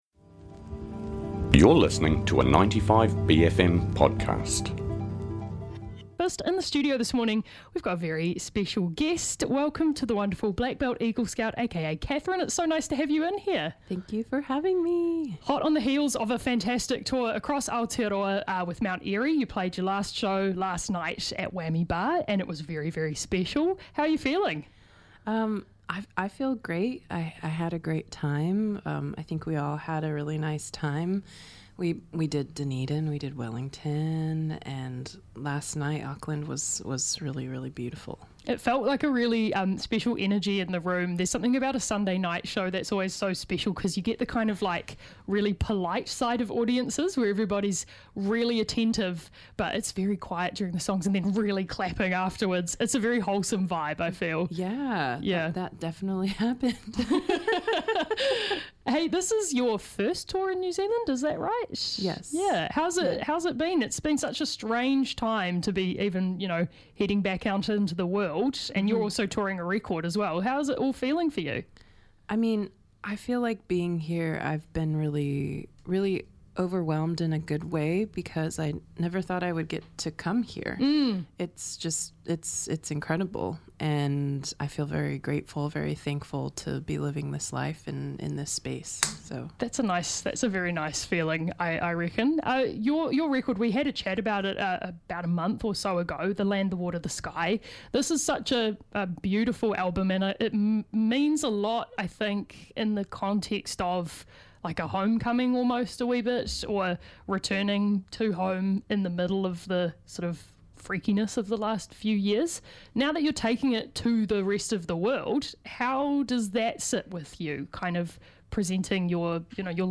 To round off the tour of Aotearoa (Dunedin, Wellington and Auckland) that they’ve done with Mount Eerie, Black Belt Eagle Scout is in the studio having a chat and playing some live acoustic tracks for us.